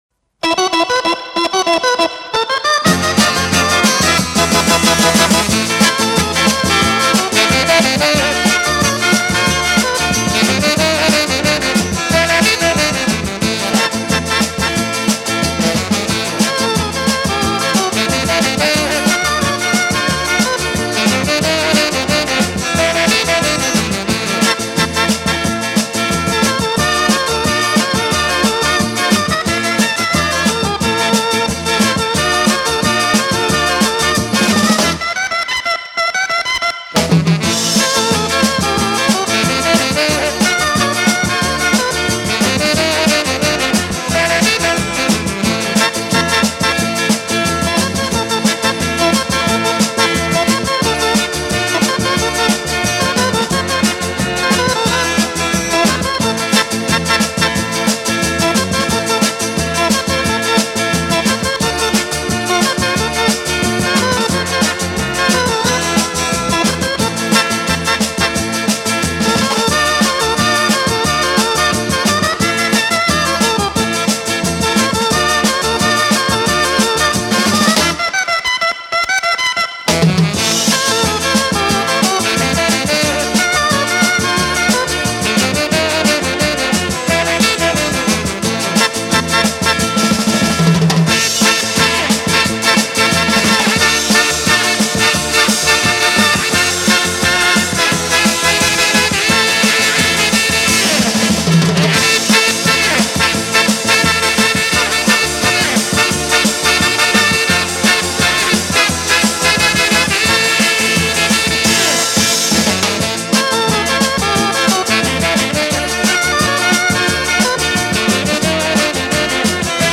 Есть похожая, только темп быстрее.
Во всех других нет сакса